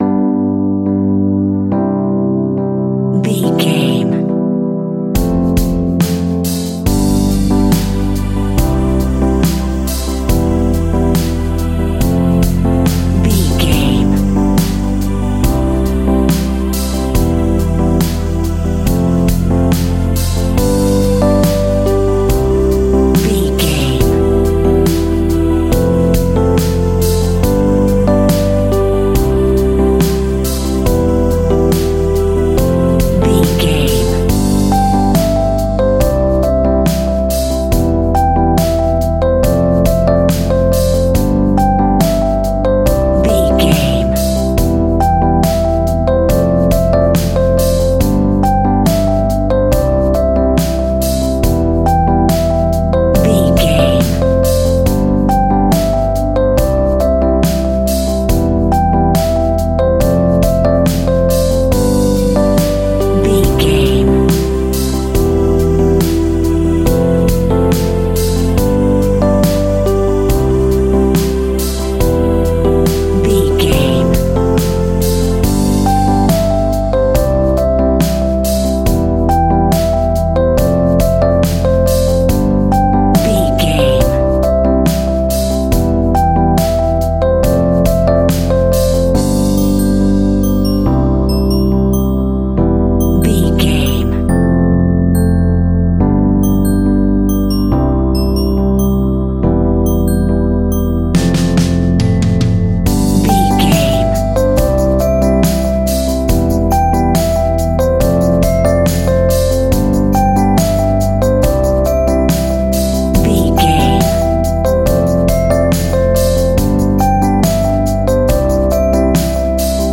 Uplifting
Ionian/Major
kids instrumentals
childlike
cute
happy
kids piano